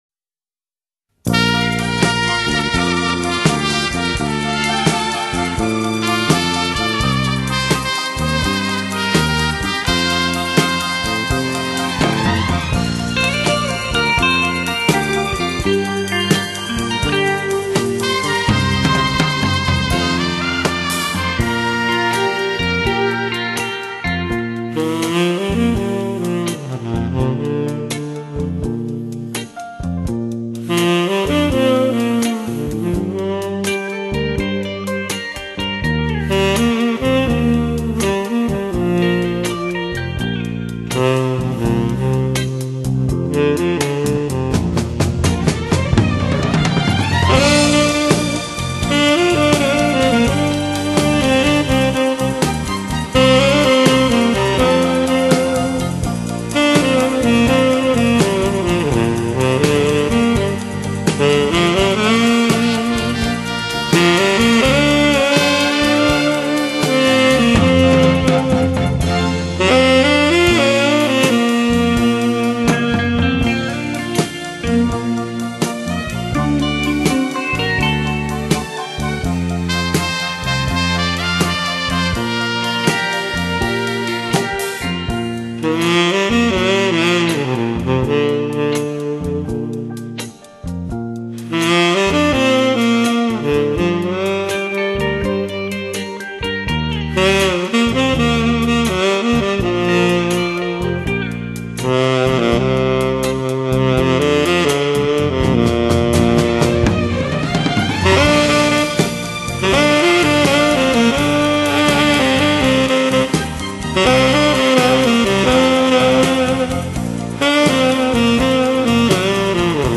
Genre: Instrumental, Saxophone